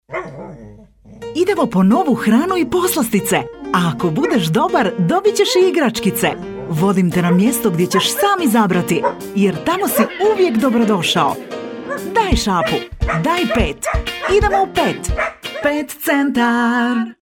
Boşnakca Seslendirme
Kadın Ses